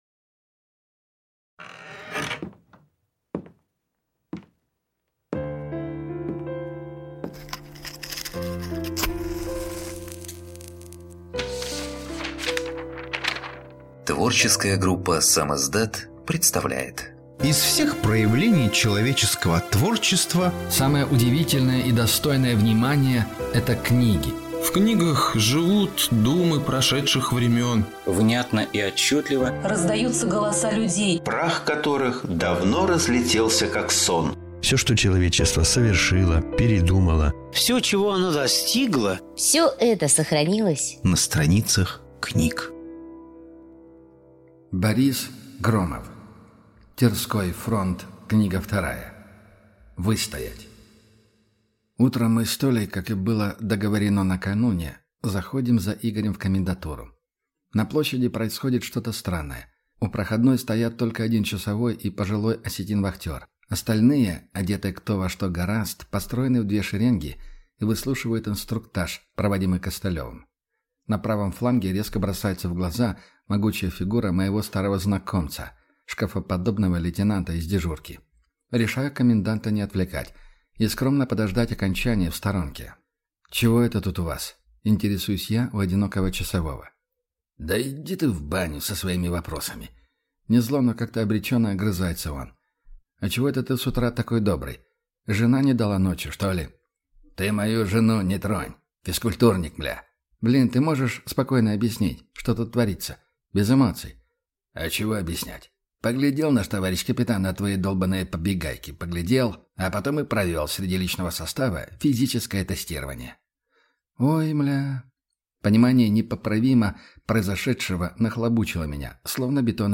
Aудиокнига Выстоять